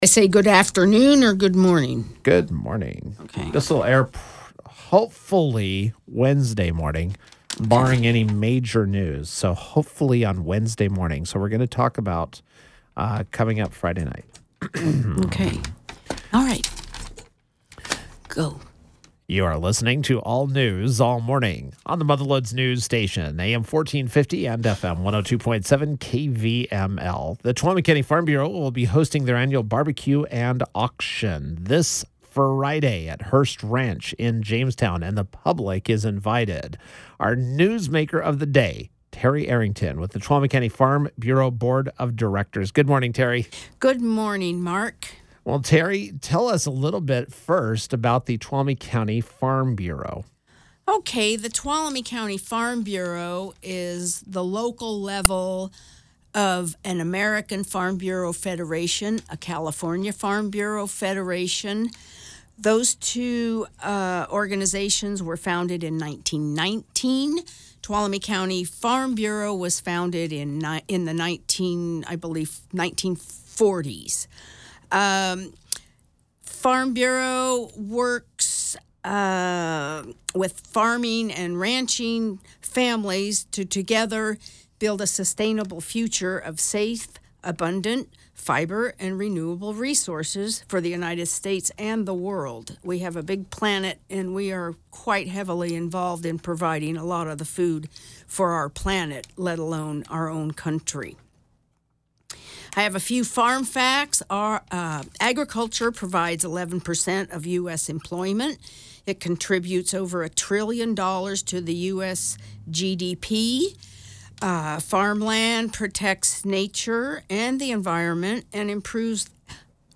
U.S. Senate Republican Leader Mitch McConnell (R-KY) delivered remarks on the Senate floor regarding the economy.